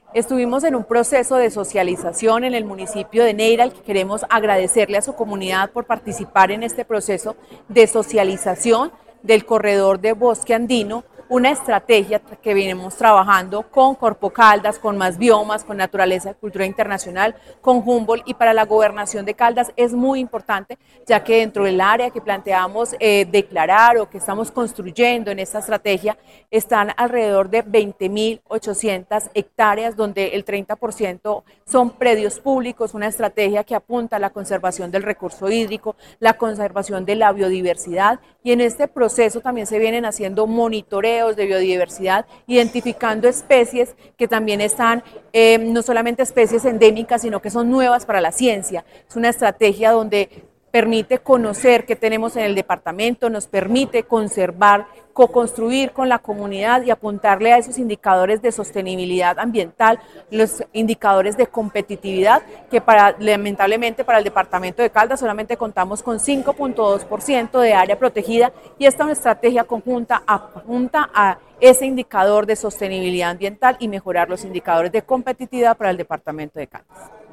Paola Andrea Loaiza Cruz, secretaria de Medio Ambiente de Caldas.